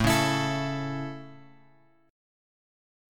A chord {5 4 x x 5 5} chord
A-Major-A-5,4,x,x,5,5.m4a